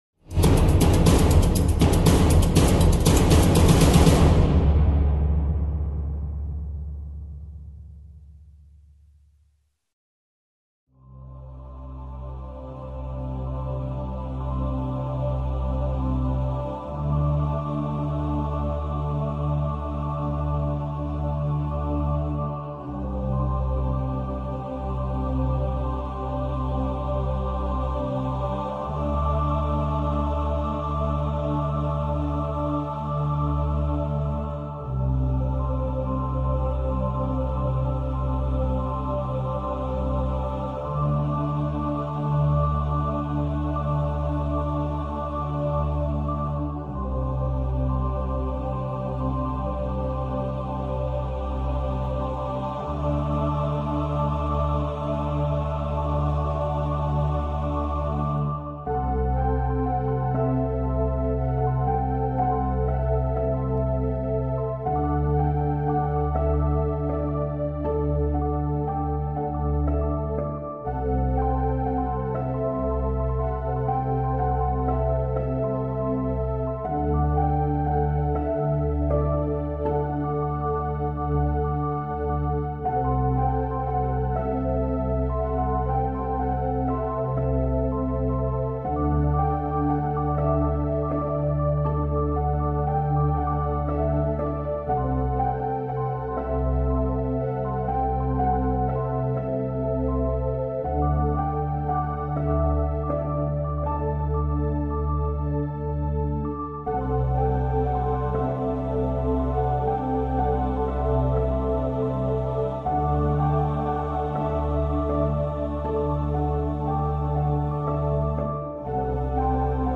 villa / DESENVOLVIMENTO PESSOAL / VIRE O JOGO - Cocriando Prosperidade - William Sanches / 2. WORKSHOP VIRE O JOGO / 8. 08 - Hora de Cocriar som de alta frequência para cocriar / Materiais Outros MP3 AUDIO MP3 - Som de alta frequência para cocriar.mp3 cloud_download